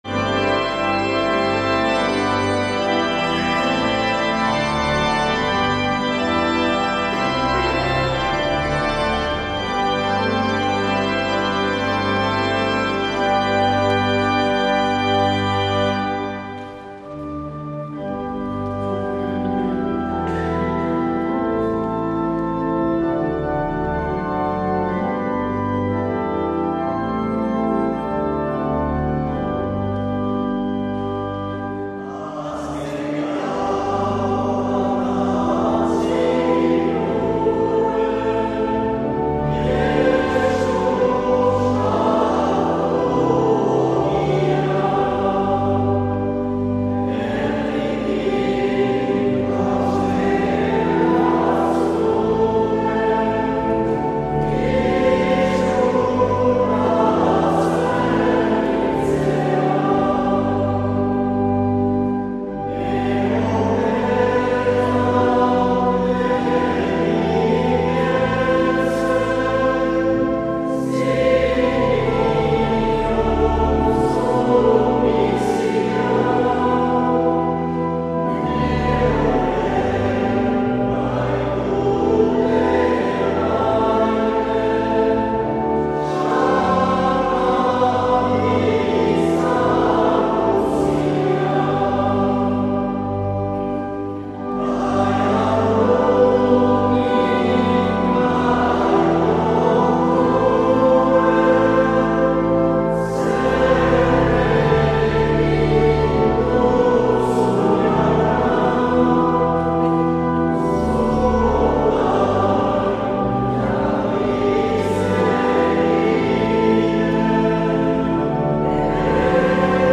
Igandetako Mezak Euskal irratietan